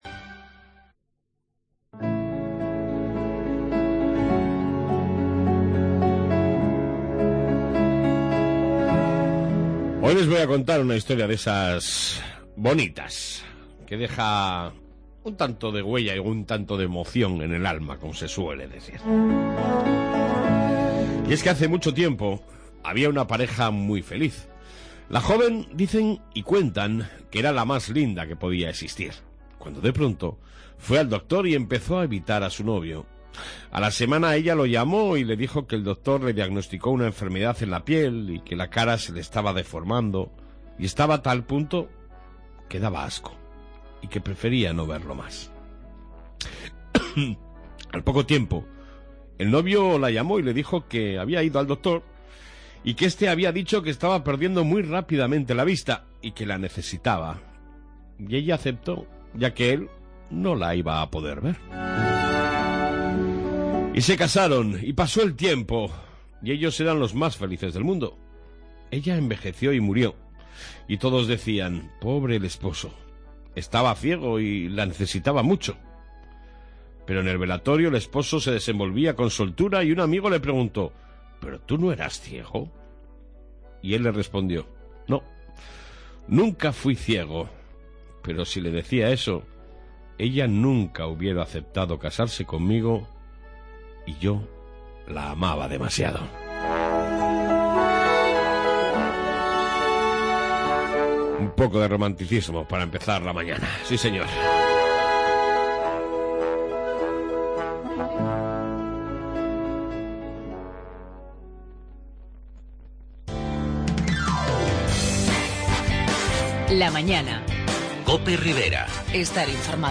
AUDIO: Reflexión diaria, Informe Policia Municipal y Entrevista con trabajadores del Horno viejo tras el cierre